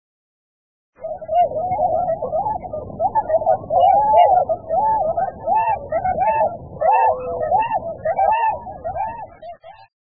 〔コハクチョウ〕コーココ／本州北部以北の湖沼などで見られる，普通・冬鳥，120p,